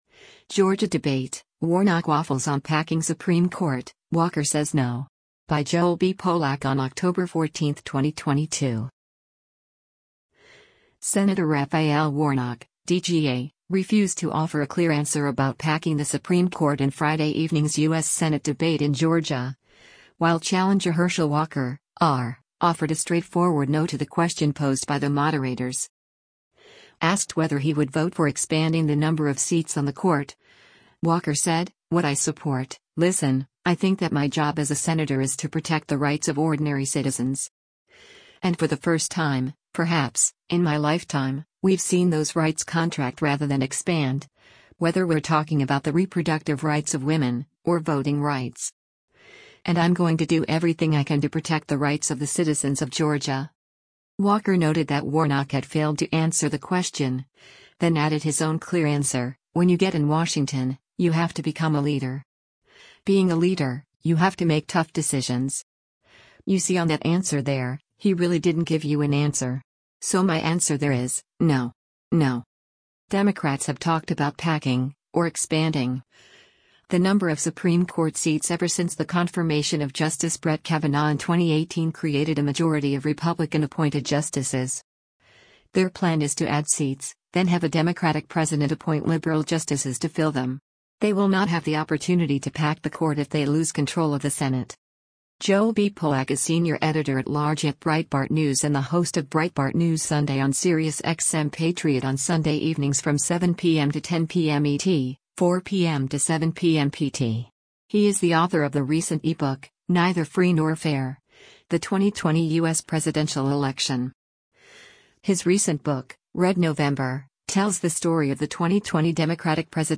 Sen. Raphael Warnock (D-GA) refused to offer a clear answer about packing the Supreme Court in Friday evening’s U.S. Senate debate in Georgia, while challenger Herschel Walker (R) offered a straightforward “no” to the question posed by the moderators.